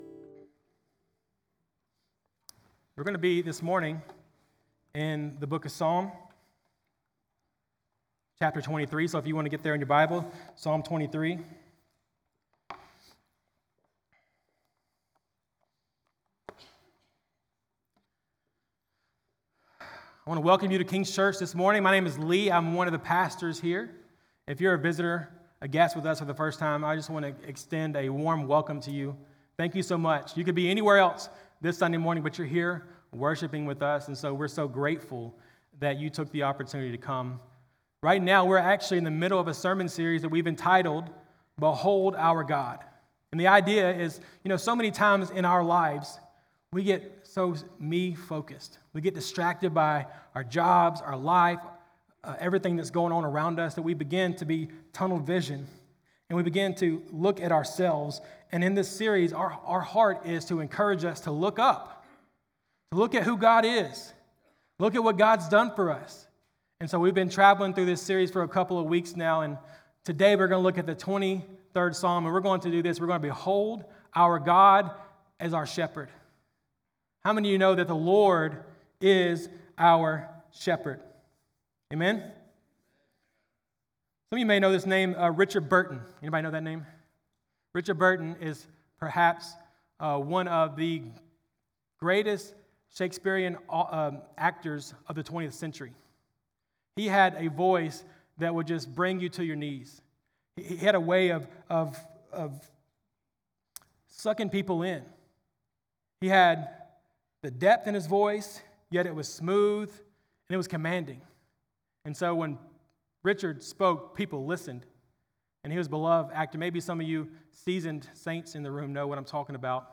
July20Sermon.mp3